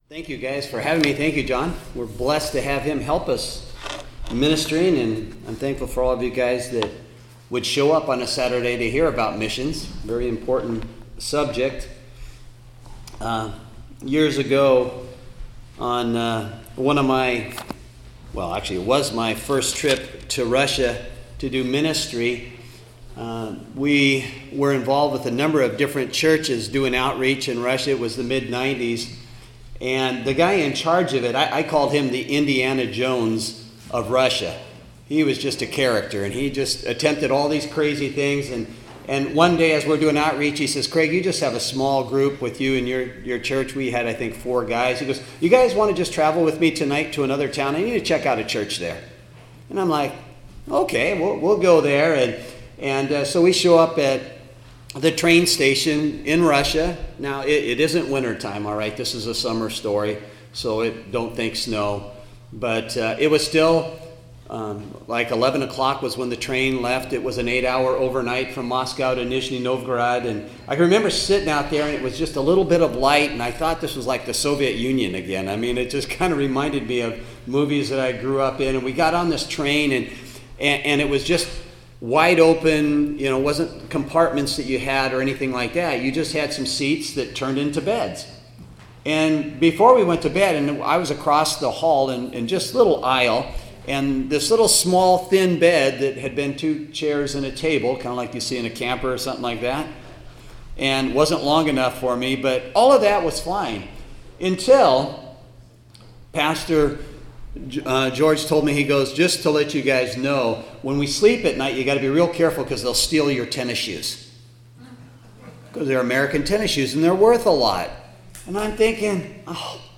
2019 Missions Conference: Missions and the New Testament
Our 2019 Missions Conference focused on the history of Church Missions; from the Old Testament, the New Testament, the last two-thousand years of Church History, and the Future of missions. The Conference included four main speakers, three breakouts, and time for corporate prayer.